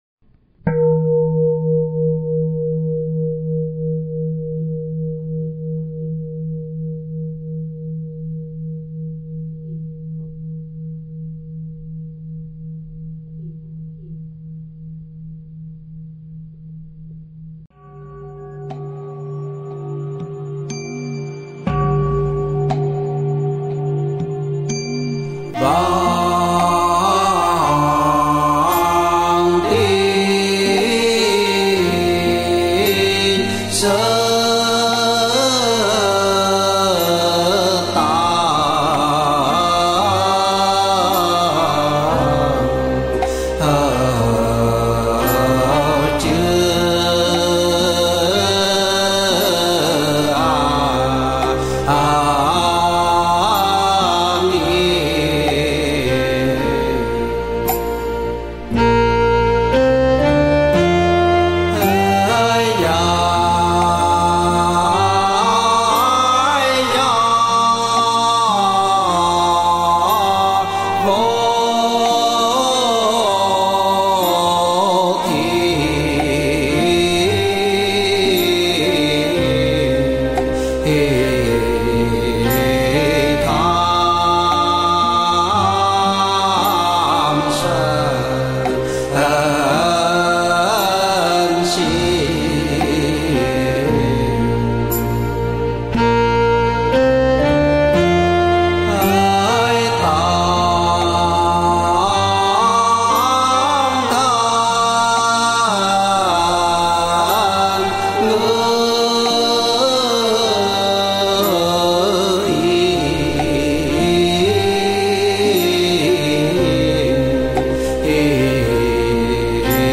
Nhạc Niệm Phật - Sám Hối A Mi Đà Phật - Chậm - Nhanh - Trung Bình
Thể loại: Nhạc Niệm Phật